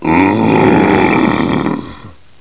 PSP: reduce size of Zombie walk VA